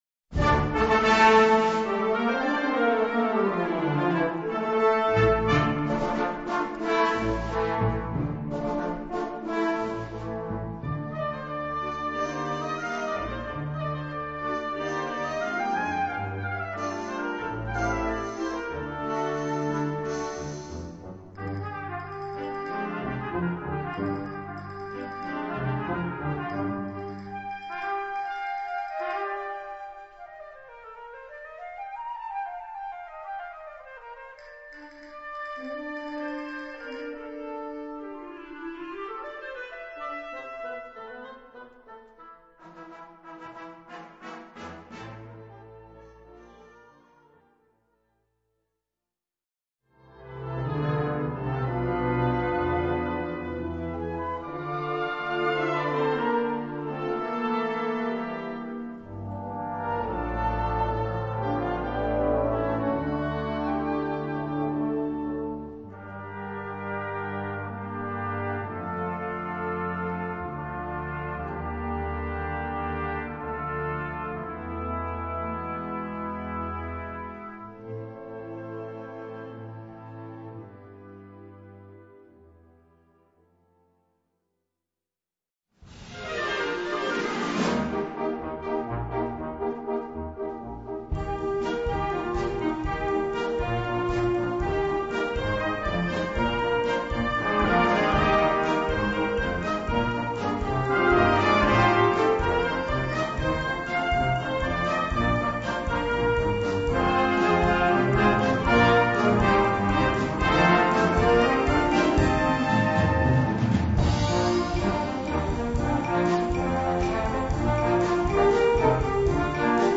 Categoria Concert/wind/brass band
Sottocategoria Musica per concerti
Instrumentation Ha (orchestra di strumenti a faito)